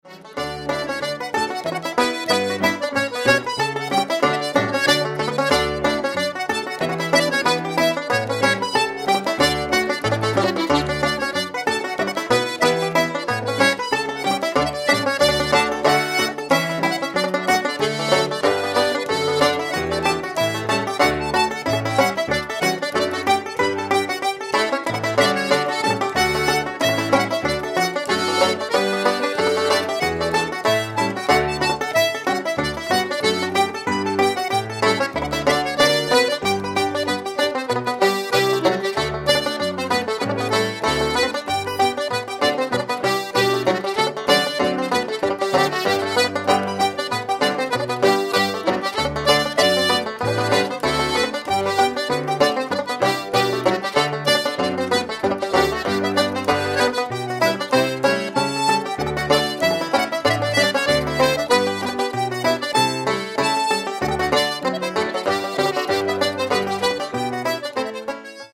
accordion